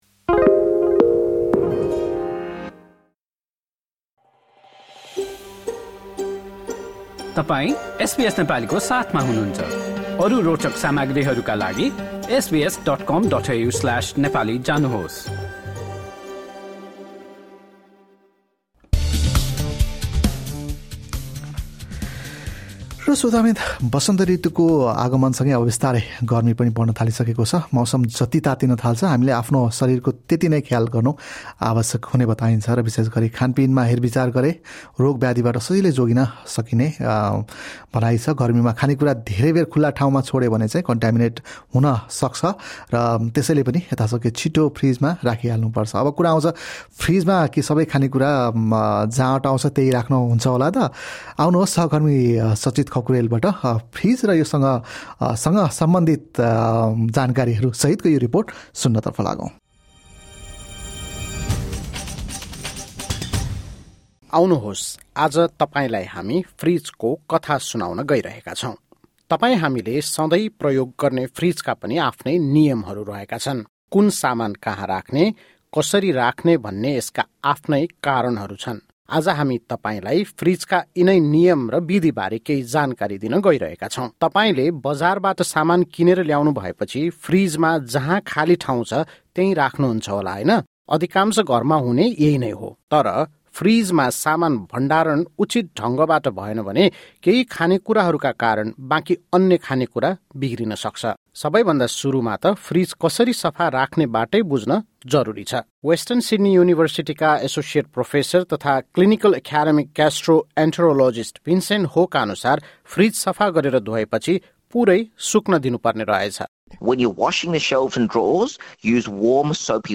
एक रिपोर्ट।